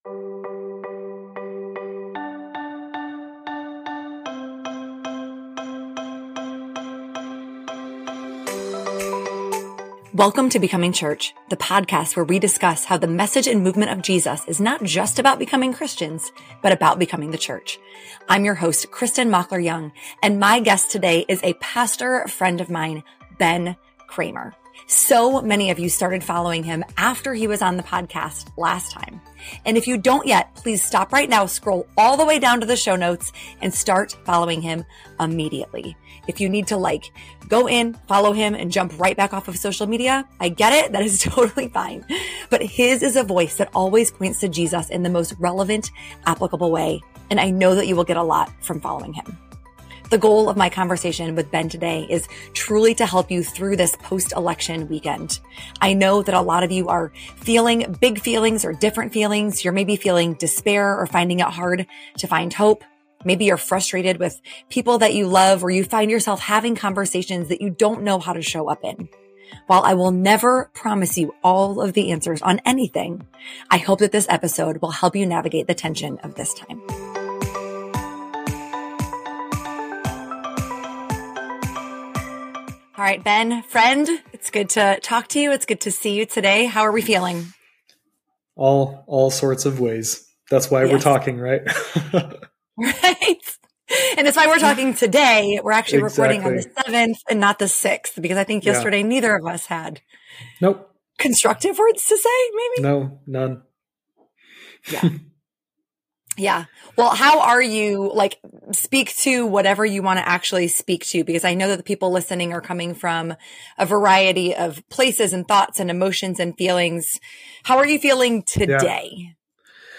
This interview between two pastors will help you hold onto hope, remind you that you are not alone, give you permission to feel your feelings and help you navigate communication with family and friends.